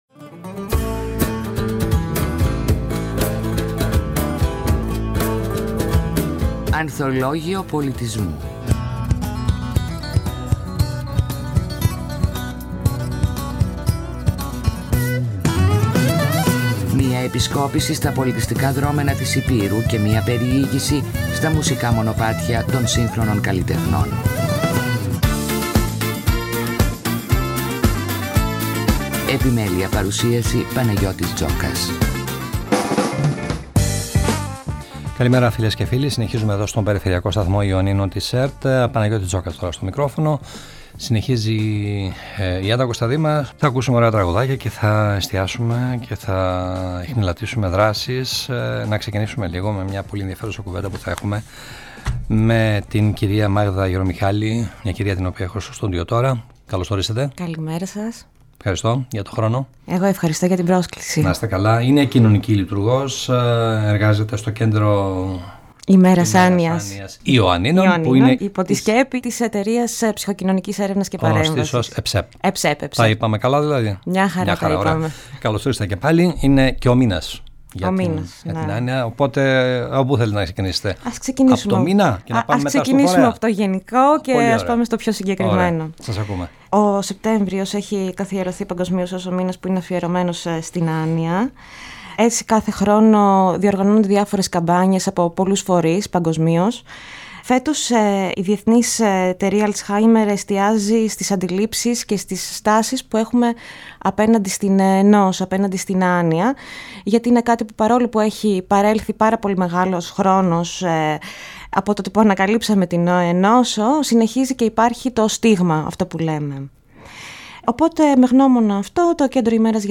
Σεπτέμβριος: Παγκόσμιος Μήνας Alzheimer 2024 | ΕΡΤ Ιωάννινα